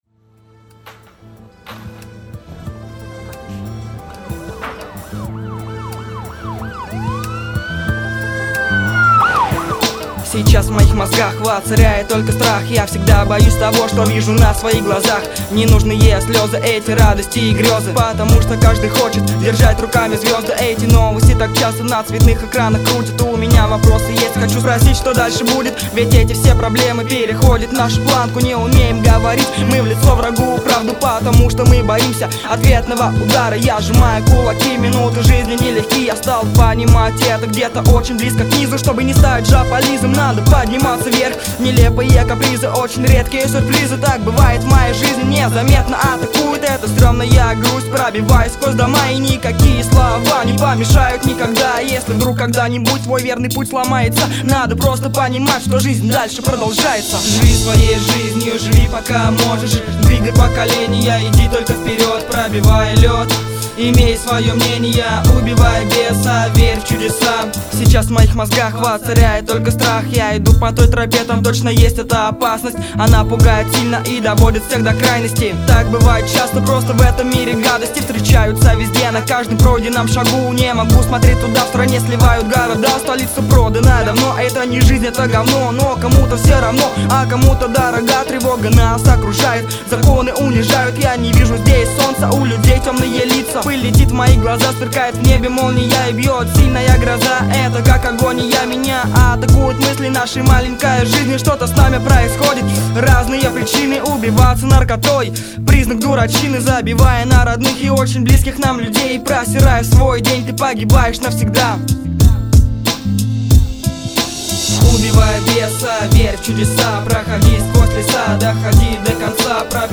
В общем ничего...но читка хромает...тяжелее надо читать...